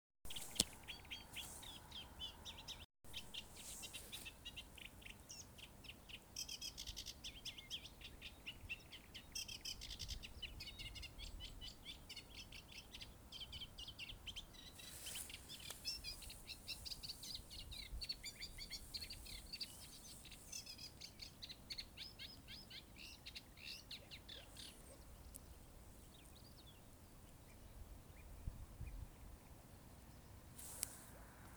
Birds -> Warblers ->
Reed Warbler, Acrocephalus scirpaceus
StatusPair observed in suitable nesting habitat in breeding season